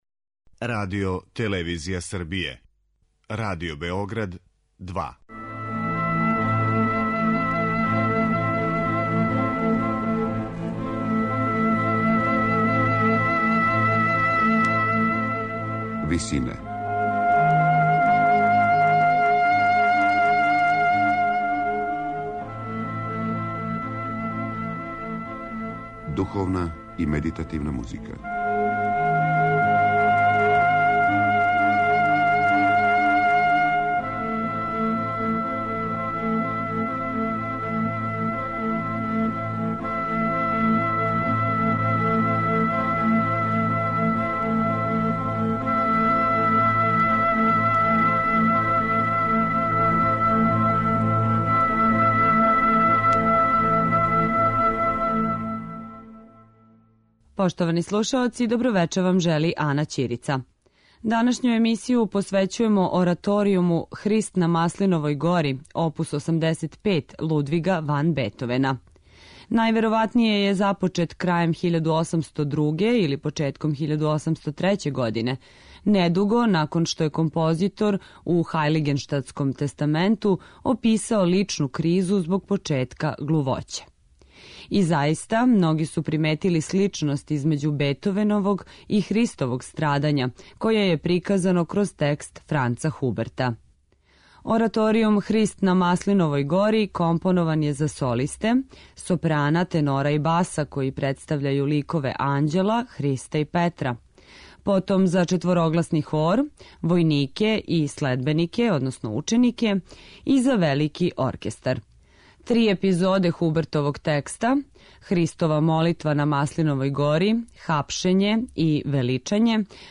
у ВИСИНАМА представљамо медитативне и духовне композиције аутора свих конфесија и епоха.